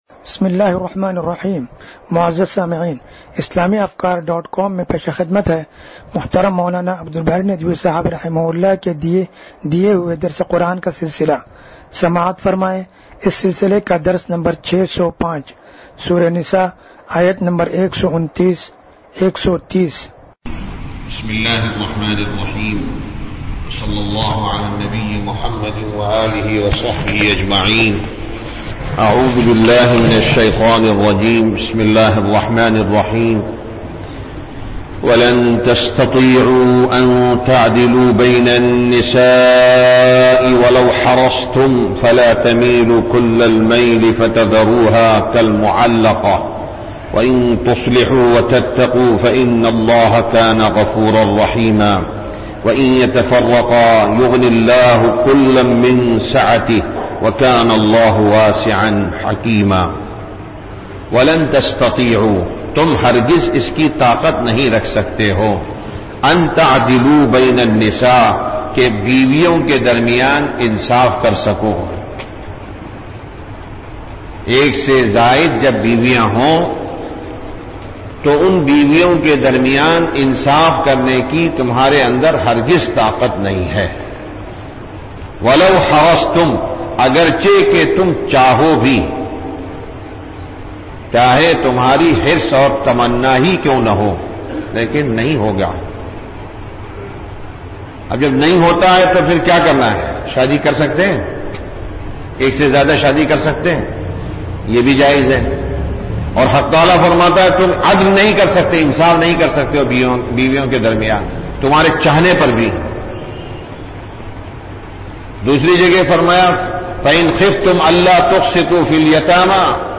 درس قرآن نمبر 0605